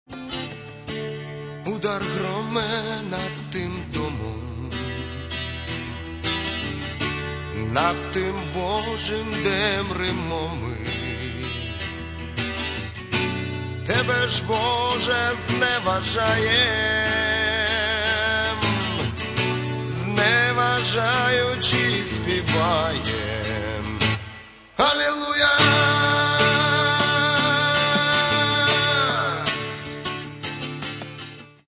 Catalogue -> Rock & Alternative -> Poetic Rock